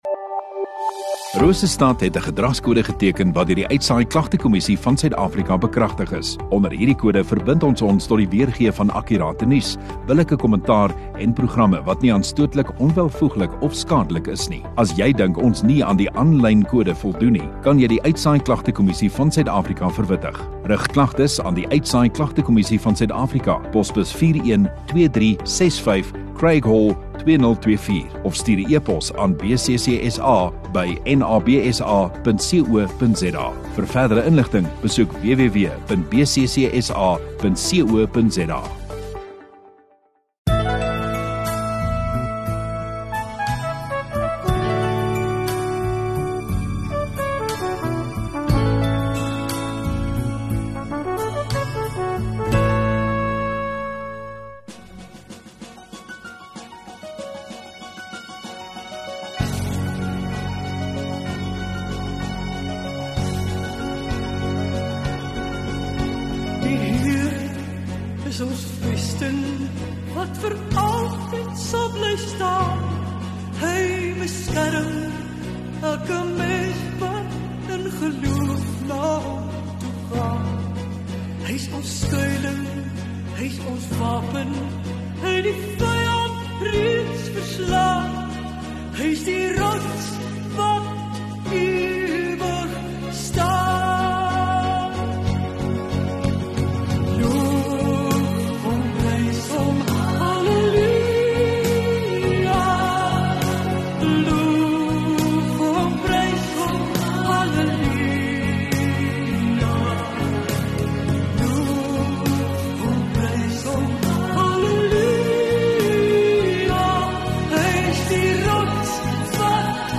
Sondagoggend Erediens